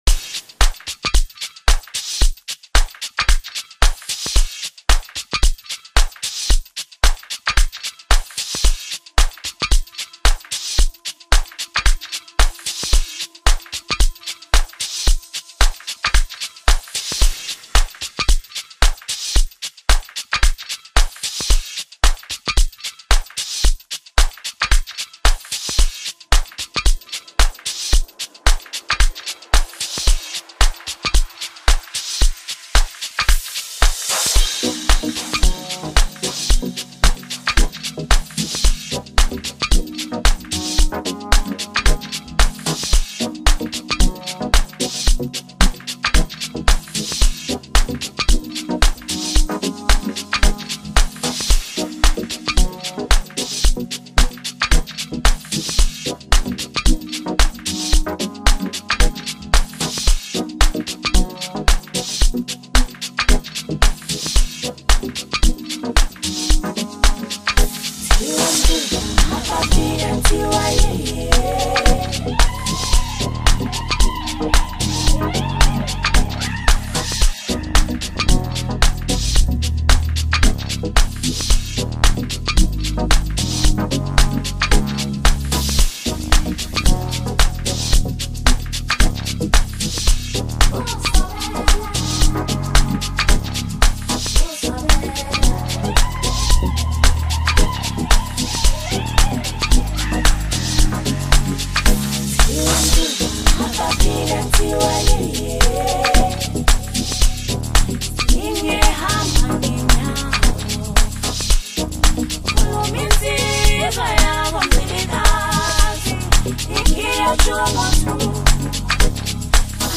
Amapiano
South African DJ and music producer